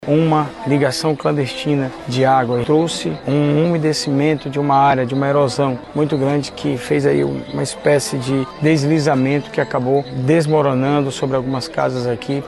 Em nota, a Prefeitura de Manaus informou que o perímetro afetado pelo deslizamento é considerado arriscado para moradias e que, por conta da ligação clandestina de tubulação de água, uma infiltração sobrecarregou a encosta, como explica o prefeito em exercício e secretário de infraestrutura, Renato Junior.